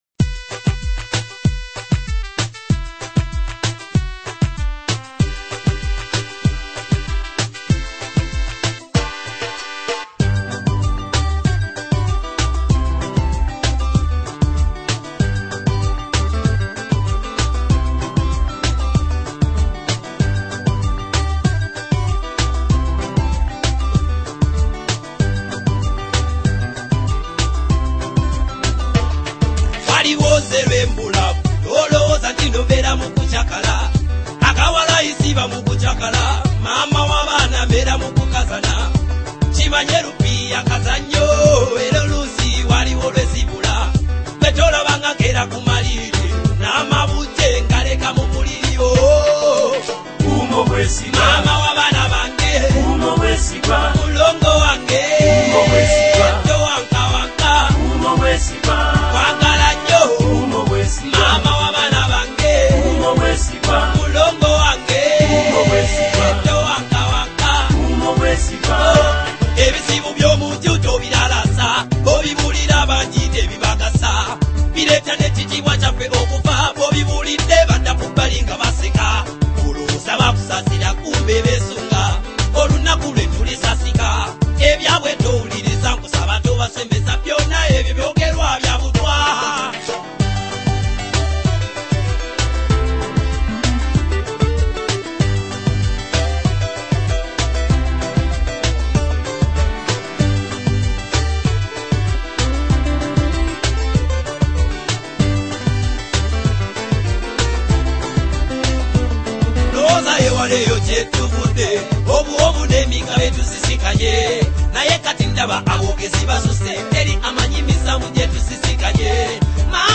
Genre: Oldies